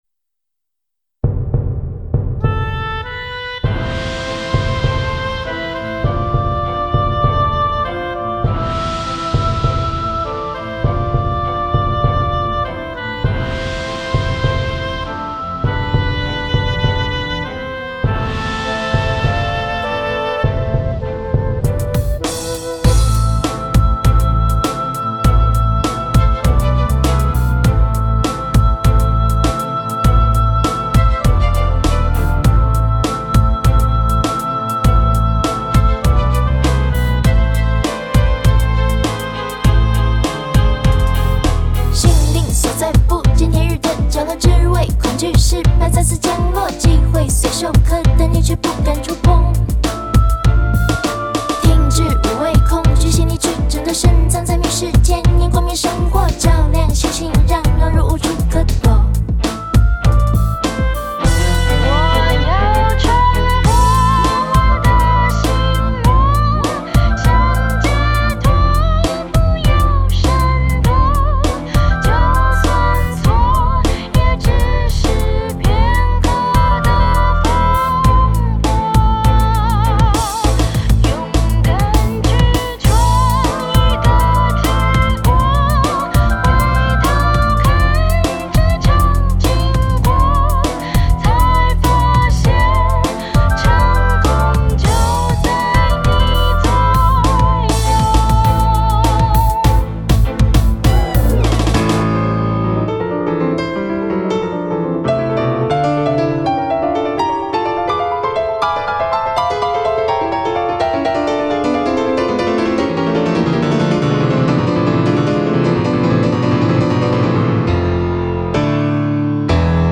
将交响乐的编制的旋律揉进现代嘻哈、摇滚、R&B的节奏，到古典声乐唱腔与流行音乐唱腔的交差运用